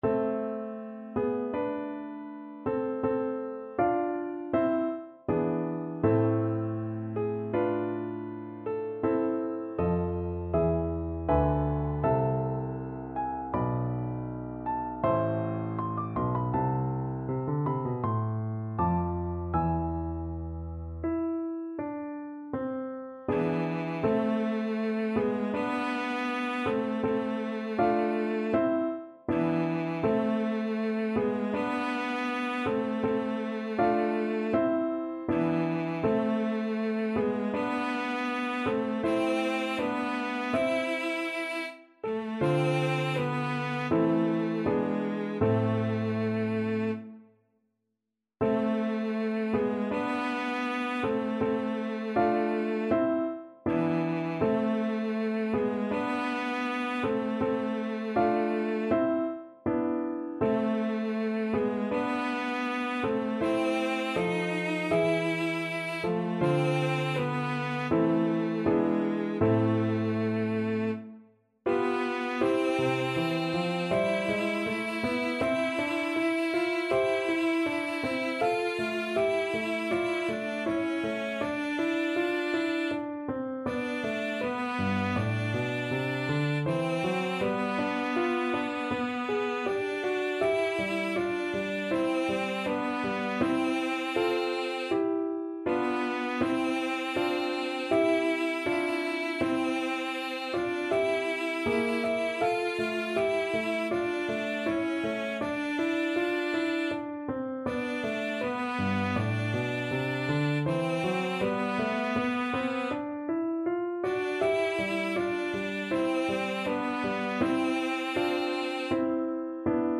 Key: A minor (Sounding Pitch)
Time Signature: 4/4
Tempo Marking: Andante =c.80
Instrument: Cello